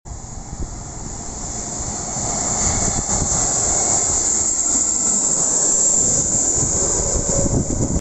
Pure A320 neo sound